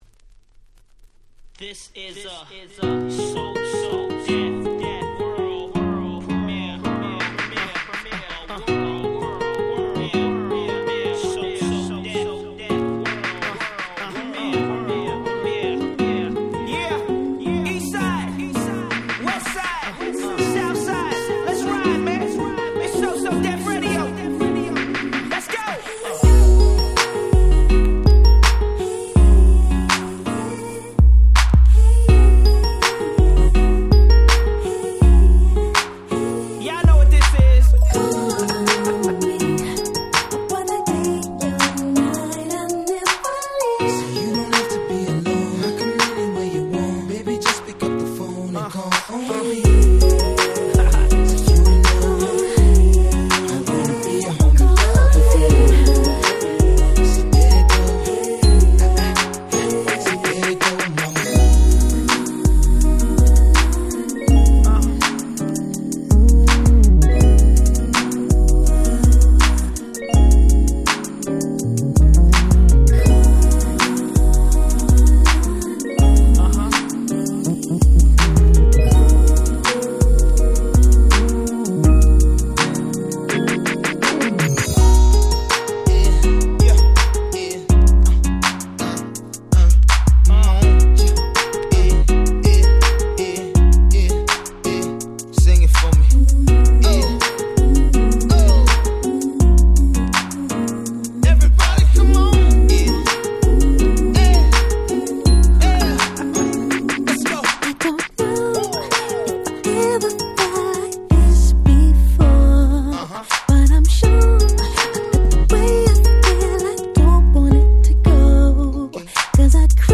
06' Super Hit R&B !!
「Dilemma」の再来か！？とまで言われた甘い甘ーい素敵な1曲！